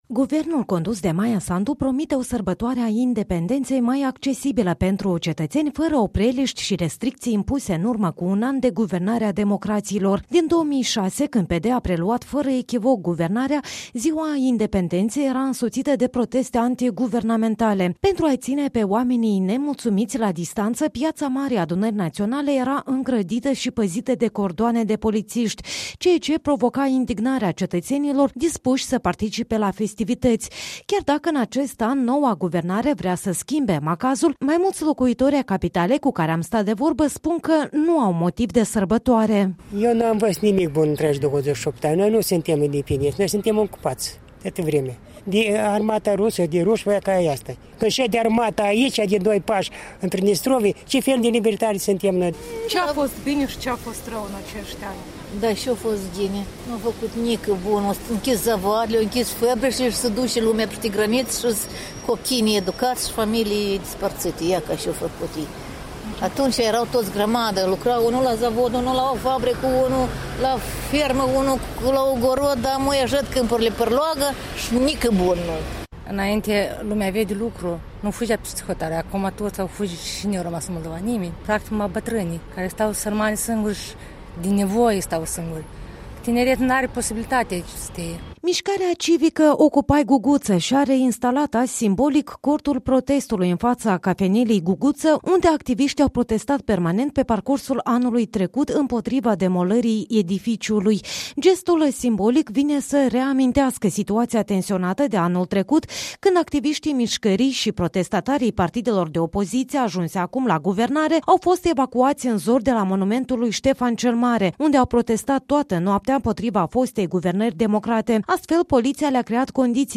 Chiar dacă în acest an noua guvernare vrea să schimbe macazul, mai mulţi locuitori ai capitalei cu care am stat de vorbă spun că nu au motiv de sărbătoare.